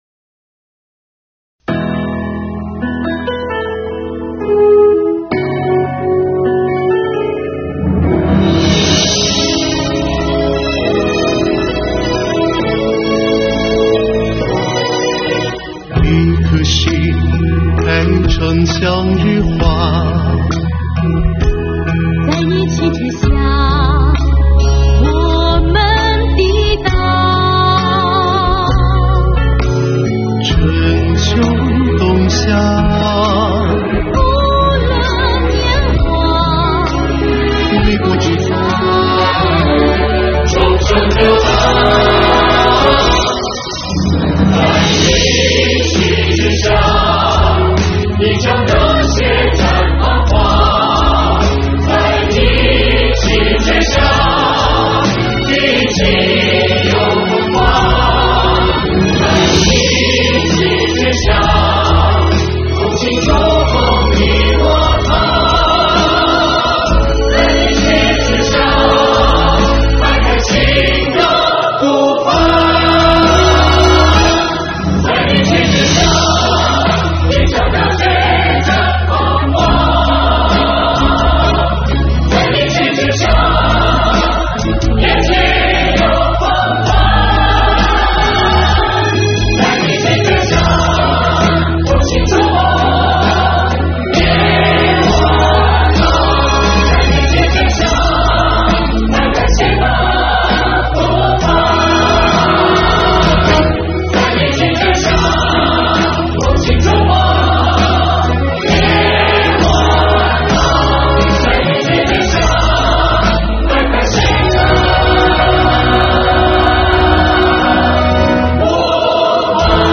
青春悠扬的歌声响彻校园